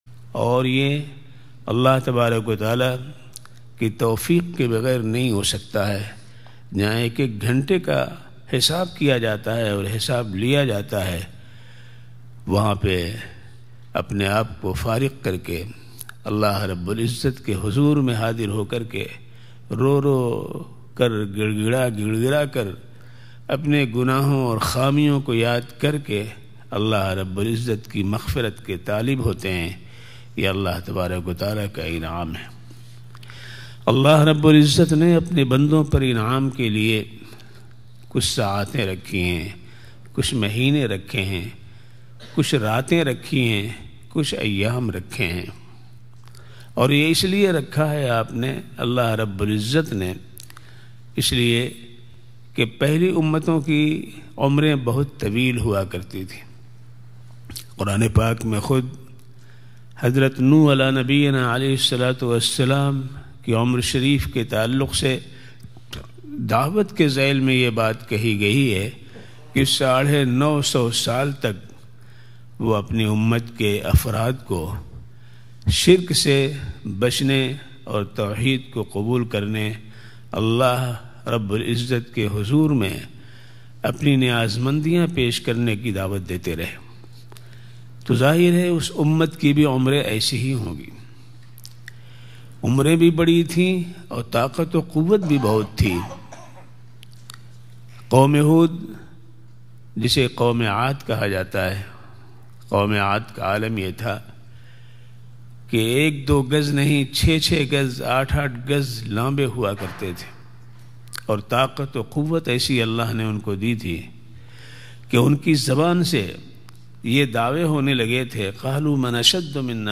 Special Urdu Lecture